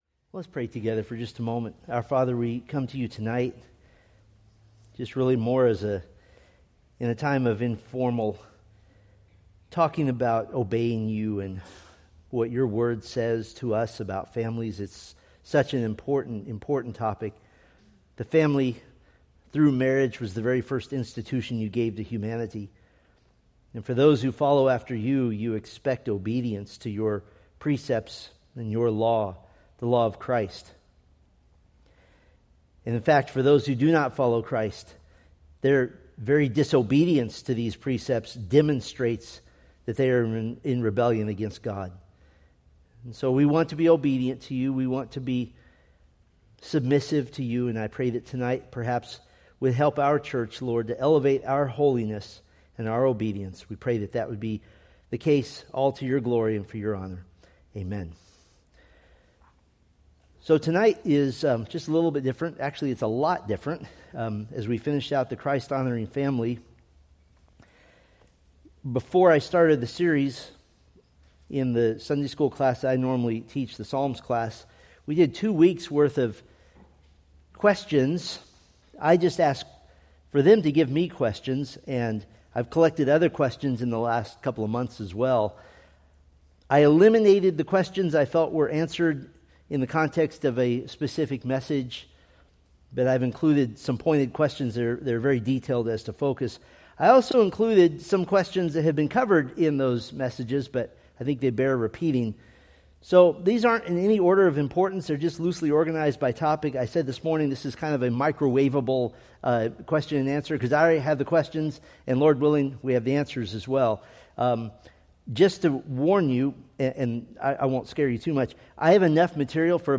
Preached September 7, 2025 from Selected Scriptures